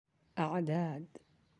(a’daad)